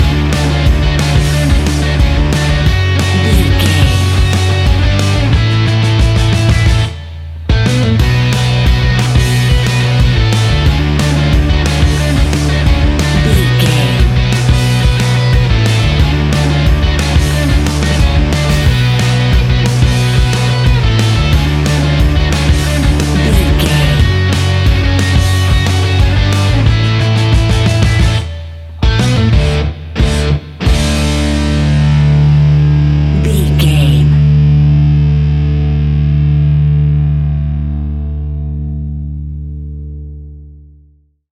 Epic / Action
Fast paced
Ionian/Major
D
hard rock
blues rock
punk metal
instrumentals
Rock Bass
heavy drums
distorted guitars
hammond organ